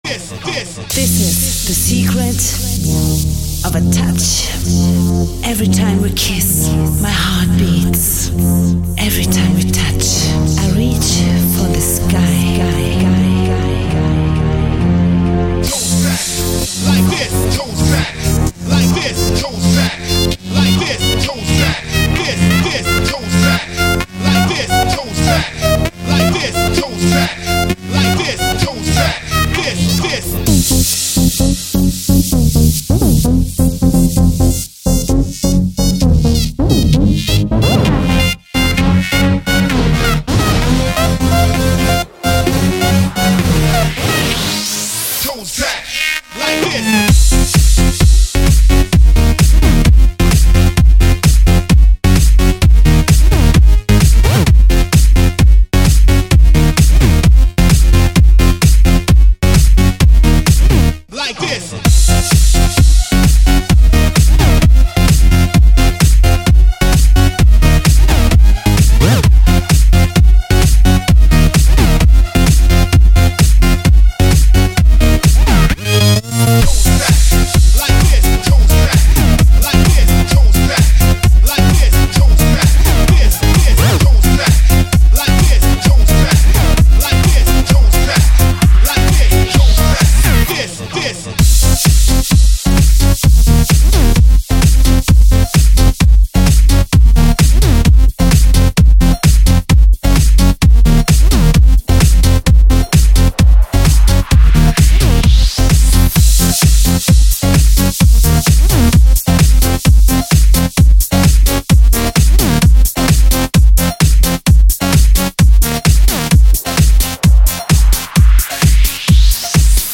Жанр: Electro House